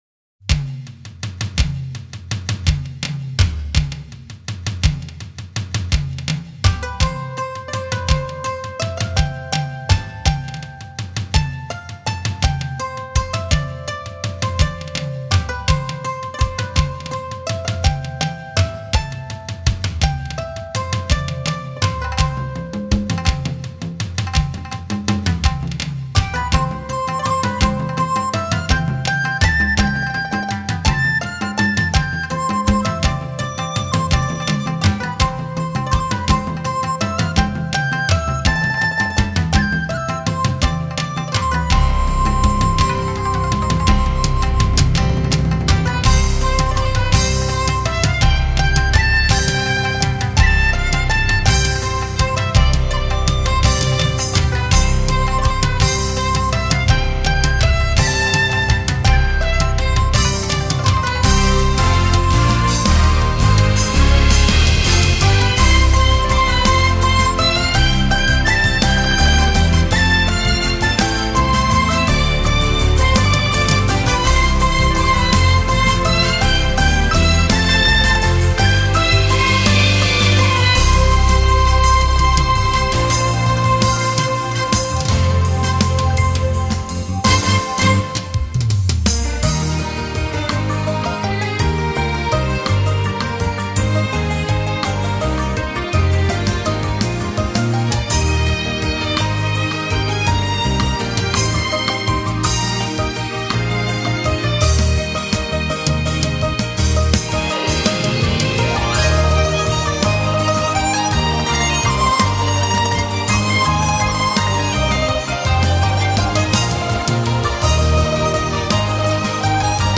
12首现代扬琴创作曲
曲风融合拉丁、苏格兰、东方小调，扬琴担任主奏，打破扬琴刻板伴奏印象，展现扬琴特色！